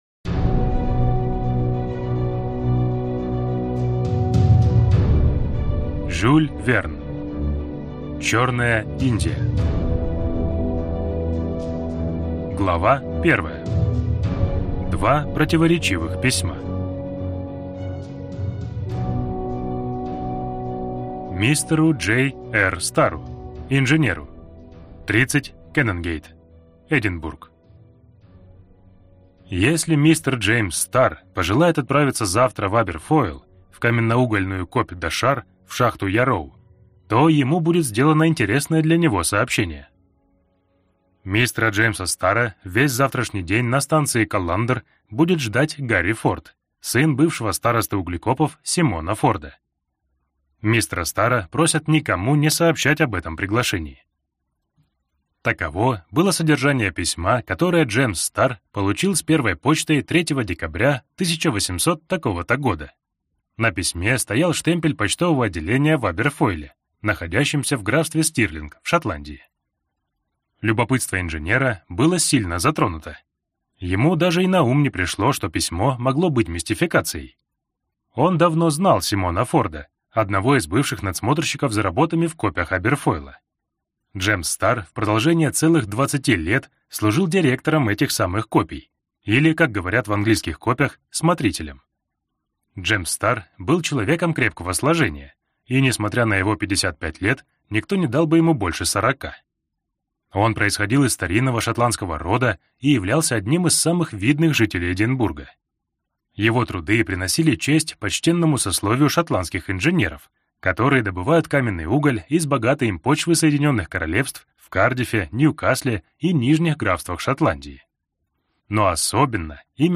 Аудиокнига Черная Индия | Библиотека аудиокниг